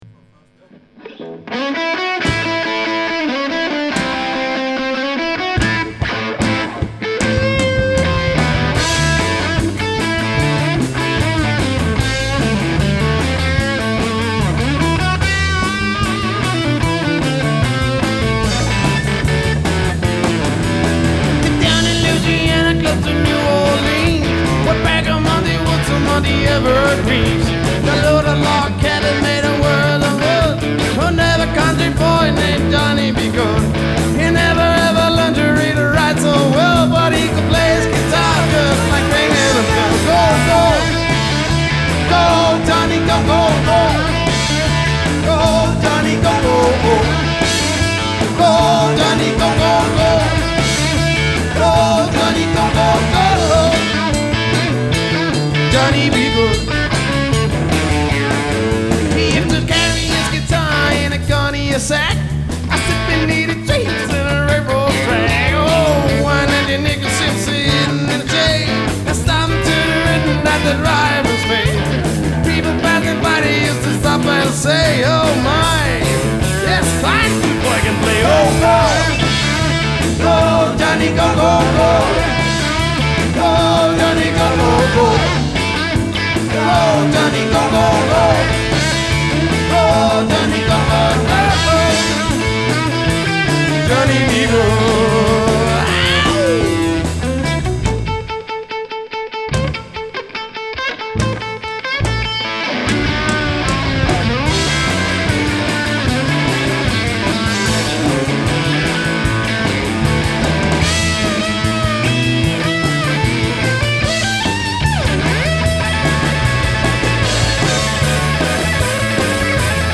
• Rockband